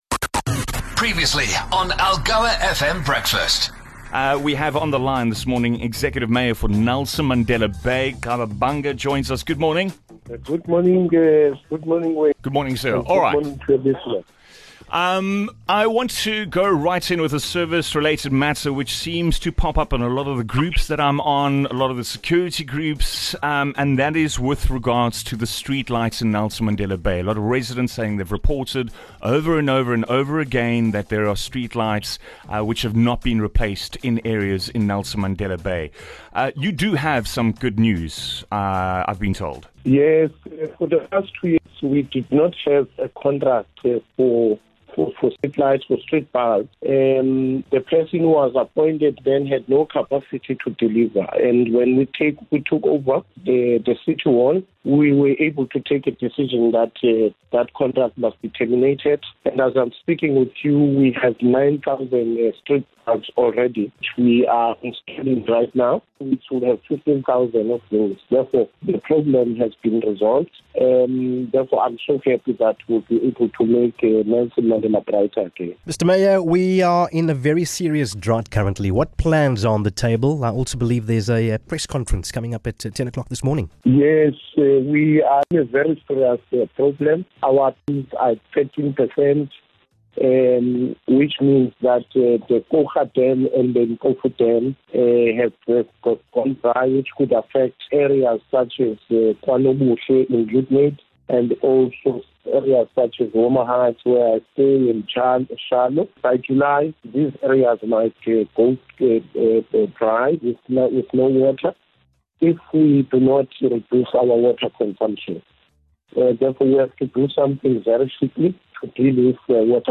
Executive Mayor Nqaba Bhanga joined the breakfast team for his bi-weekly update on pressing issues facing the city.